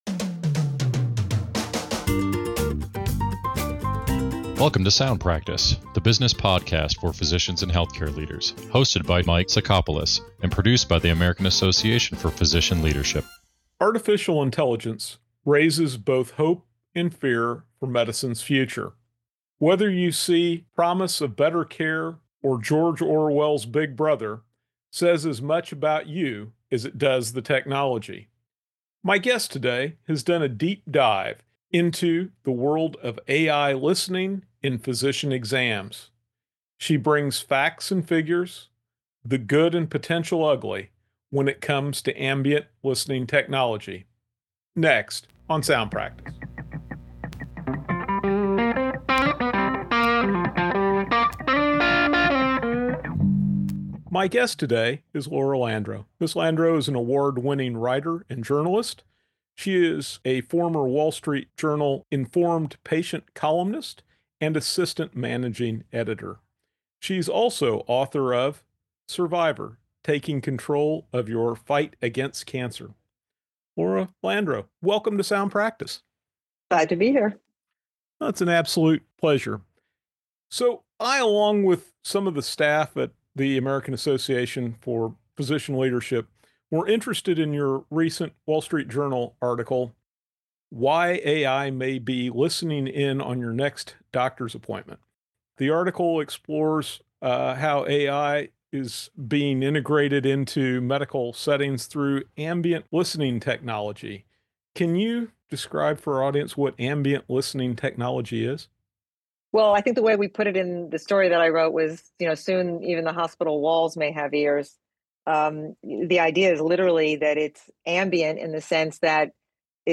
In this episode of SoundPractice, we dive into an engaging and informative discussion on the future of AI in healthcare and its implications for both doctors and patients.